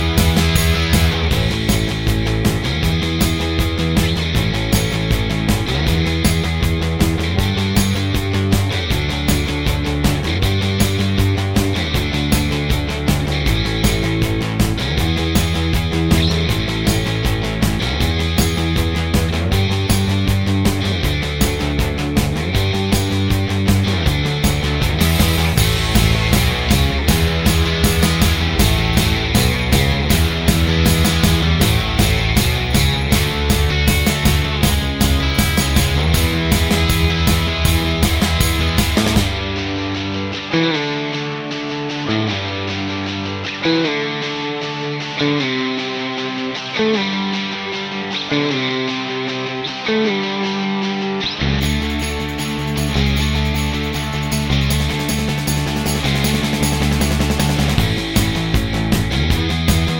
no Backing Vocals Punk 2:35 Buy £1.50